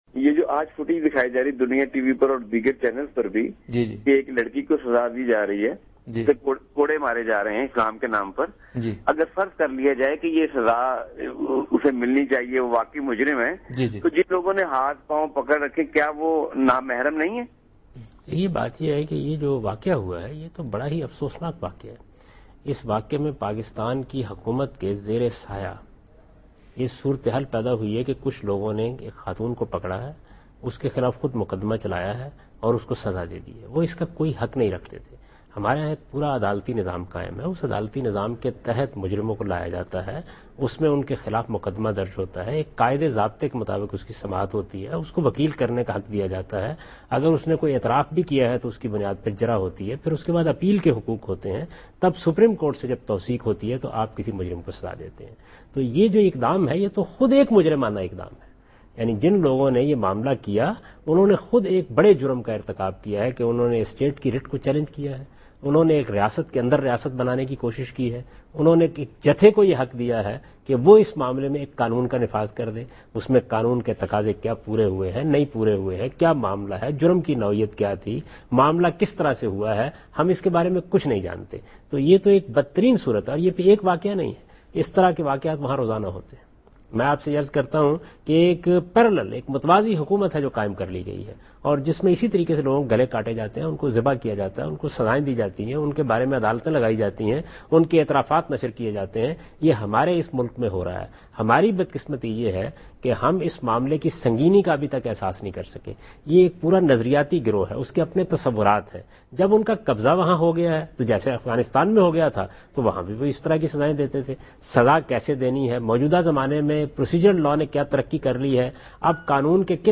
Category: TV Programs / Dunya News / Deen-o-Daanish /
Javed Ahmad Ghamidi answers a question regarding "Flogging of a Girl in Sawat" in program Deen o Daanish on Dunya News.